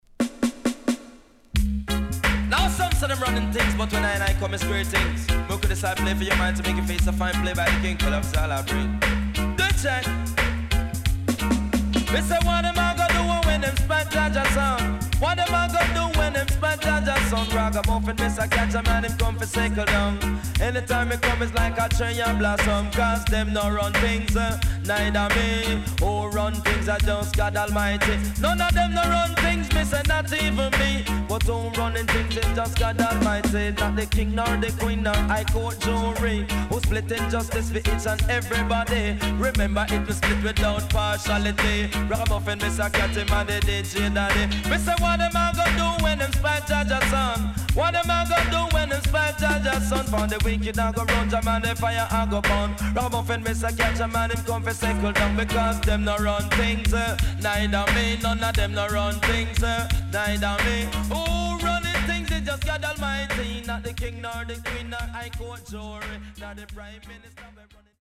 HOME > REISSUE USED [DANCEHALL]
SIDE B:少しチリノイズ入ります。